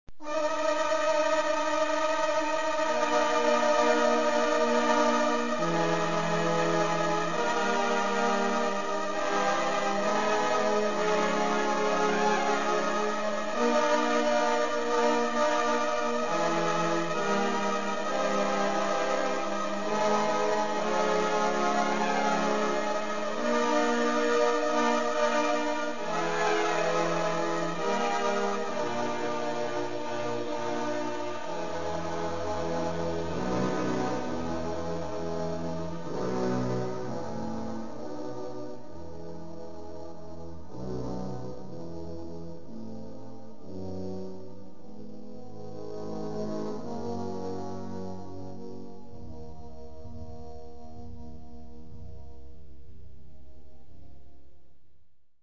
Kategorie Blasorchester/HaFaBra
Unterkategorie Zeitgenössische Bläsermusik (1945-heute)